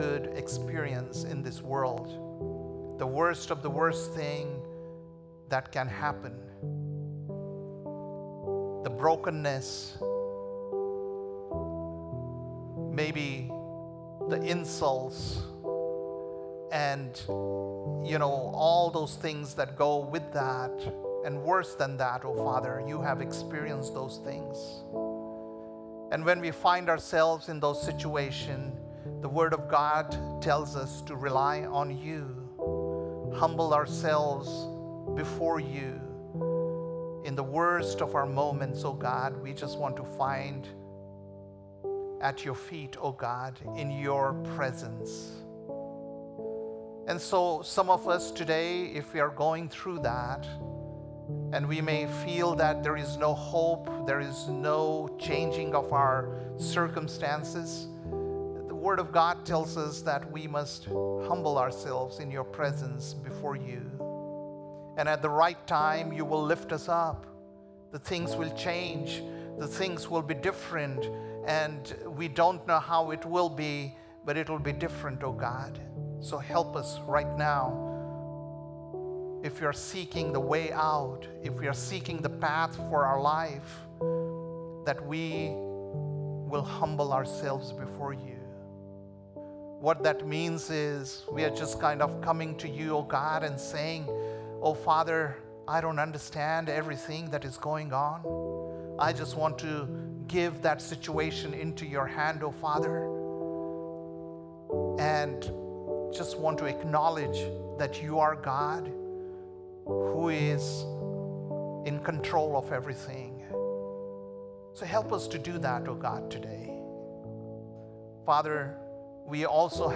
June 22nd, 2025 - Sunday Service - Wasilla Lake Church